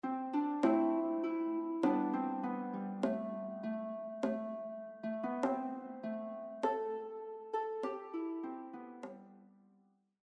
“Siúil a Rún” is a traditional Irish song that expresses the sadness of a woman facing her lover’s departure for war. This song, alternating between English and Irish, belongs to the macaronic style.